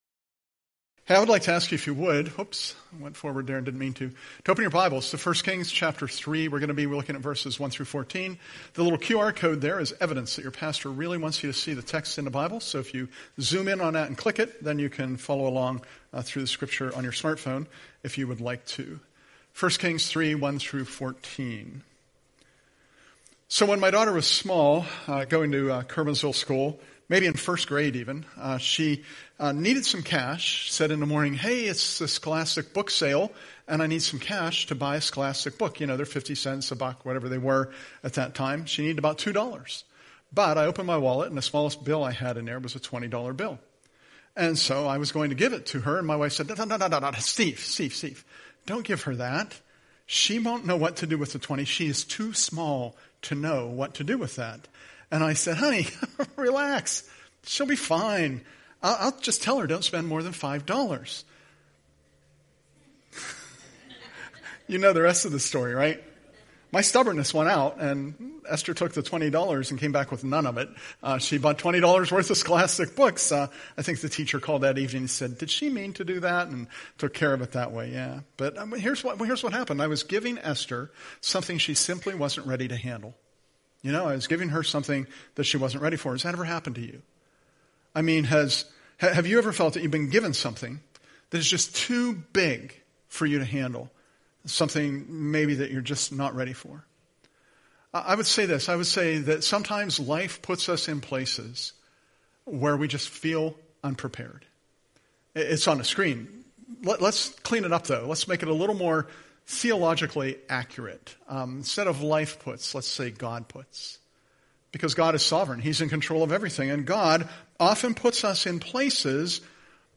Prepared to Receive Wisdom :: WISDOM – Curwensville Alliance Church Podcasts